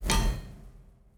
Blacksmith 03.wav